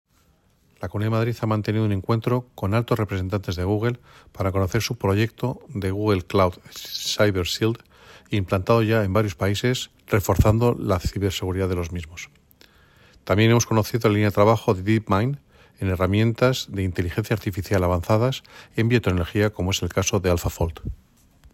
Declaraciones del Consejero